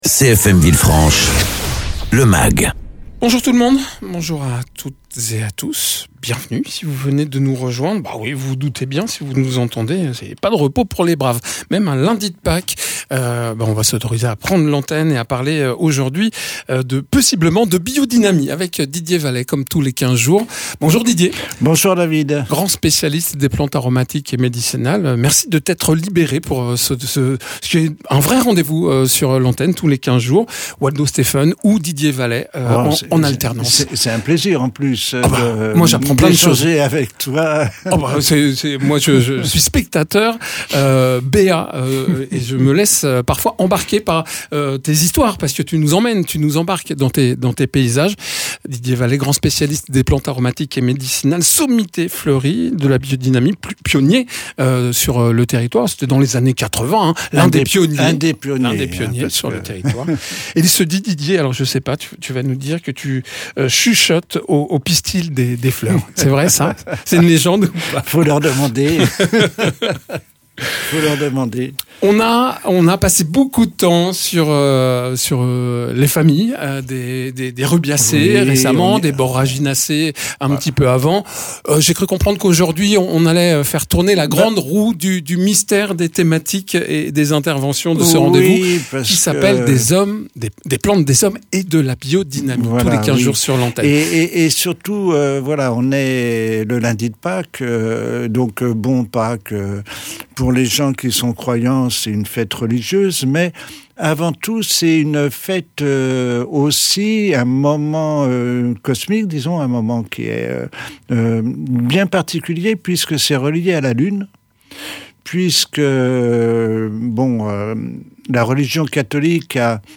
paysan spécialisé dans les plantes aromatiques et médicinales et la biodynamie.